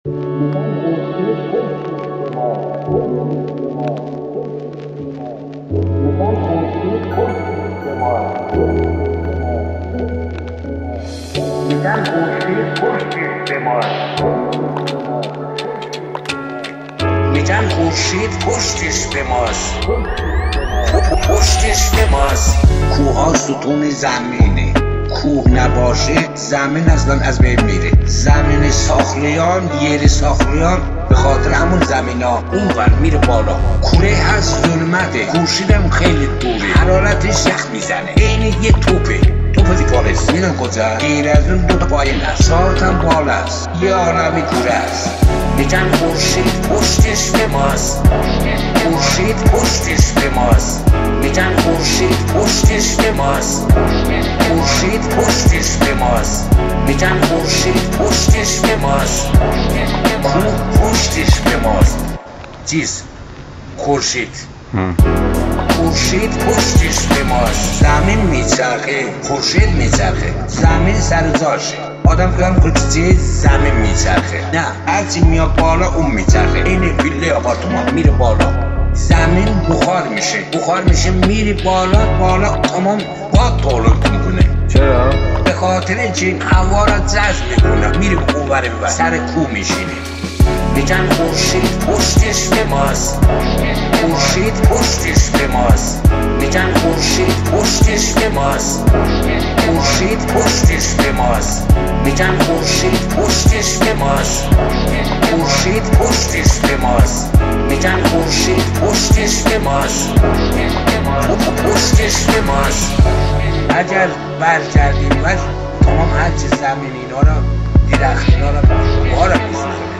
ریمیکس جدید